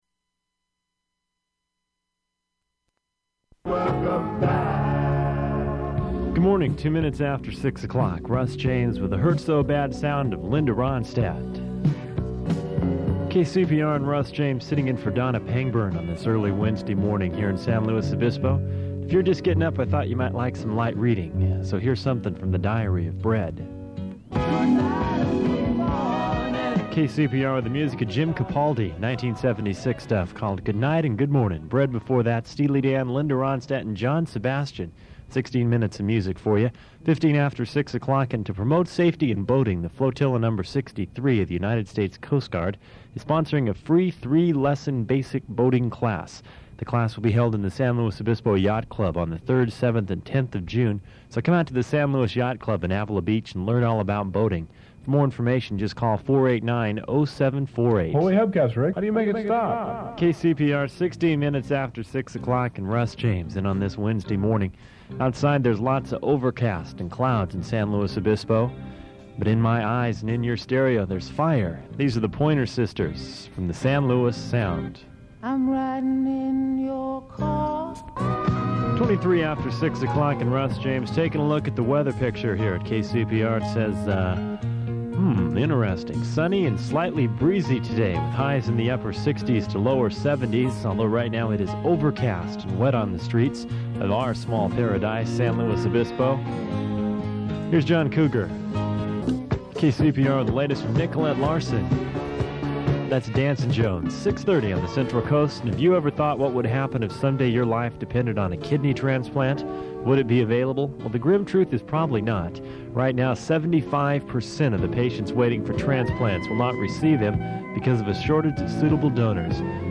[recording cuts off]
Slowed-down jingle with outtakes
Form of original Open reel audiotape